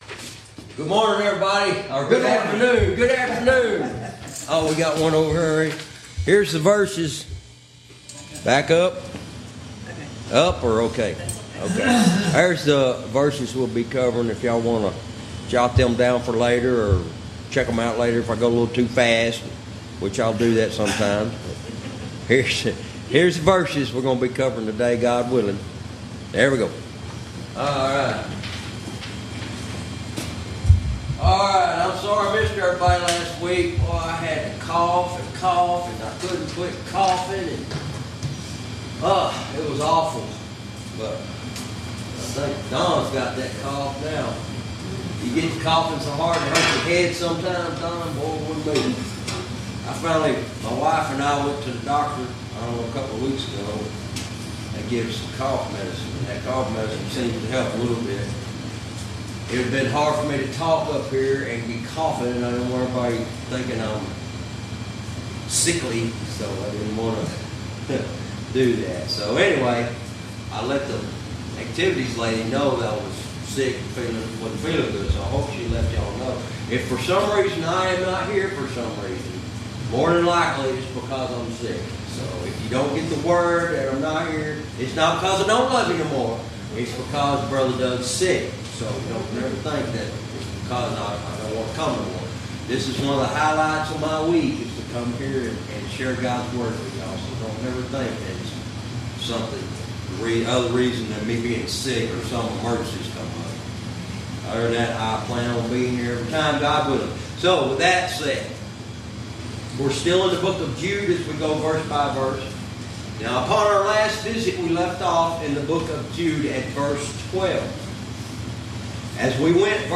Verse by verse teaching - Lesson 51 verse 12